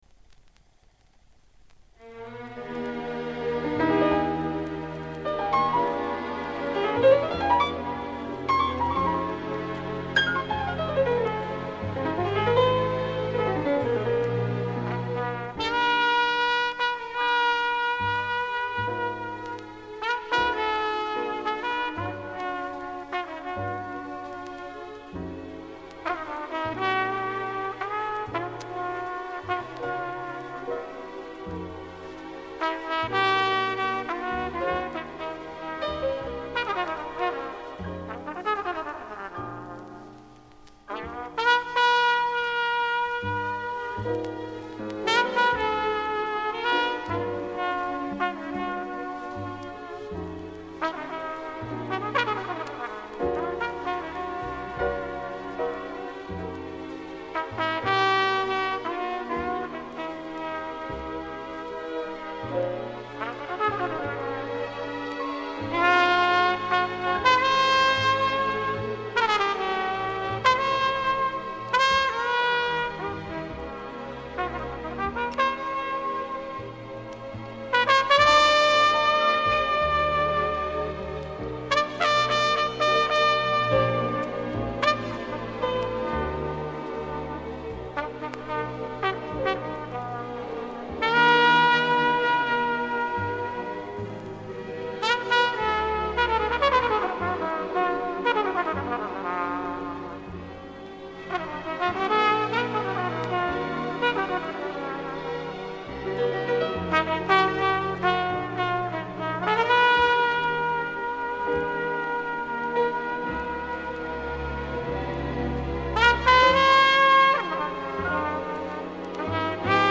Еше один джазовый музыкант-трубач из Чехословакии